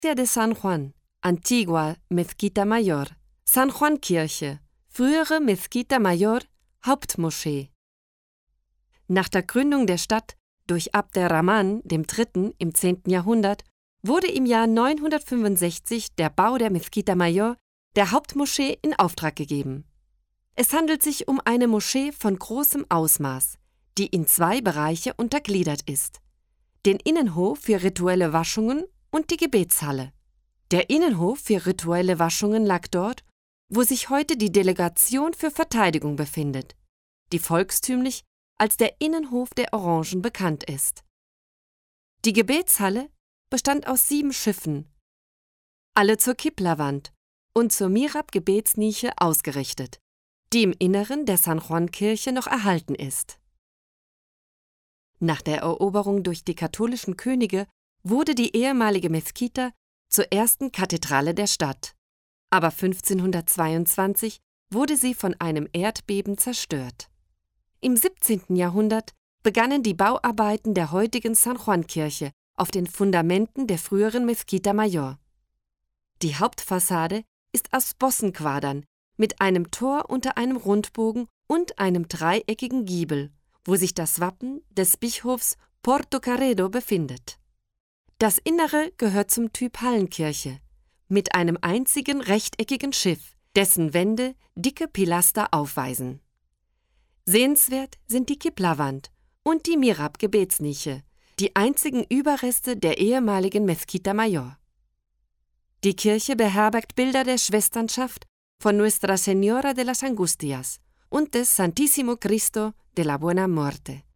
AUDIOGUIA-ALMERIA-ALEMAN-4-iglesia-de-san-juan.mp3